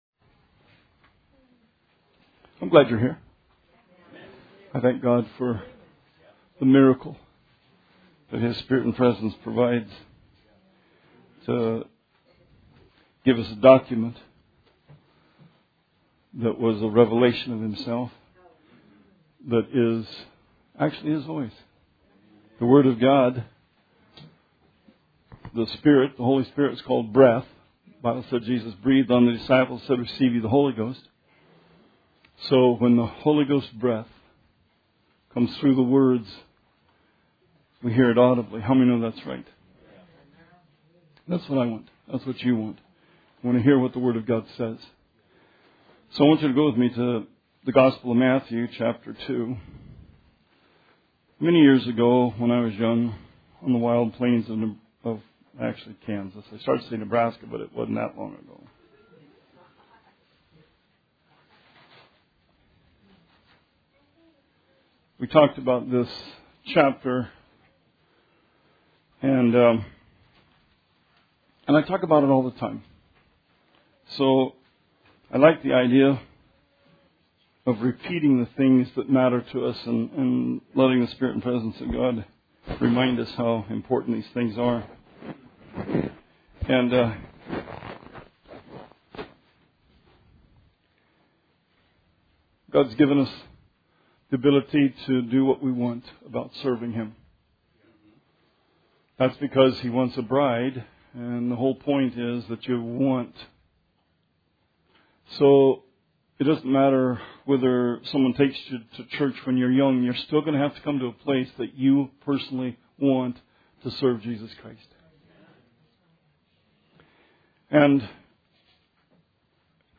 Sermon 7/2/17 – RR Archives
Sermon 7/2/17